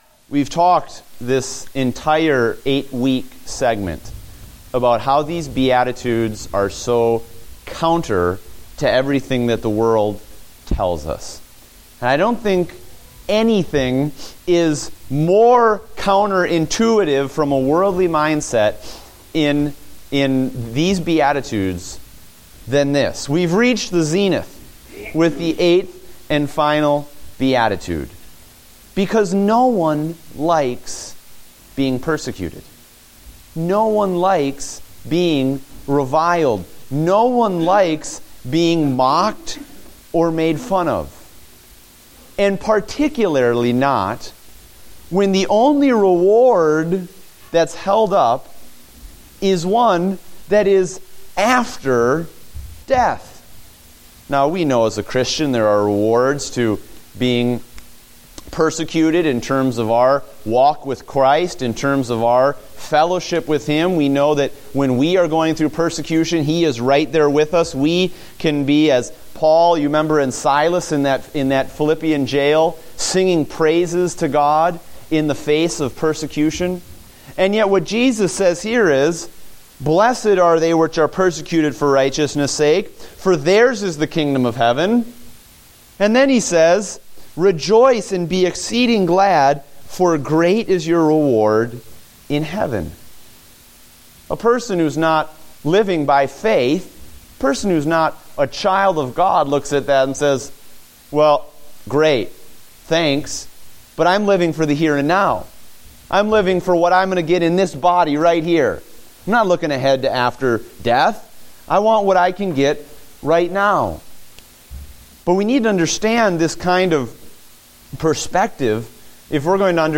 Date: May 3, 2015 (Adult Sunday School)